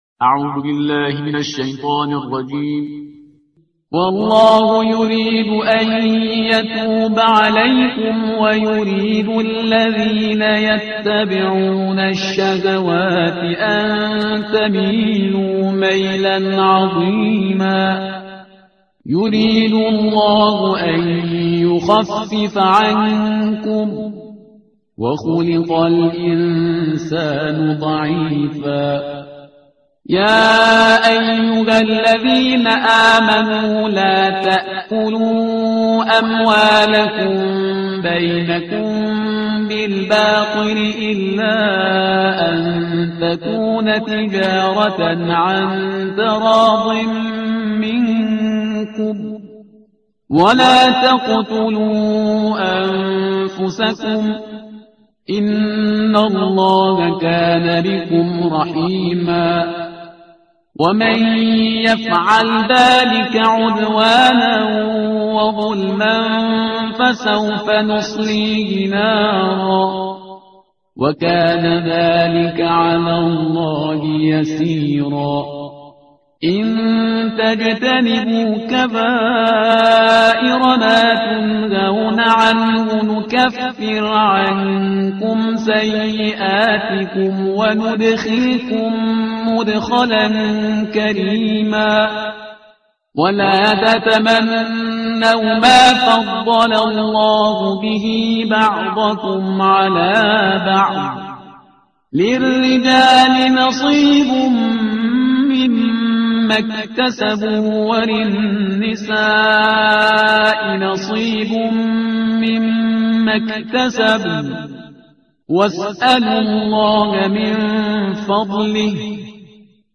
ترتیل صفحه 83 قرآن با صدای شهریار پرهیزگار
Parhizgar-Shahriar-Juz-05-Page-083.mp3